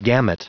Prononciation du mot gamut en anglais (fichier audio)
Prononciation du mot : gamut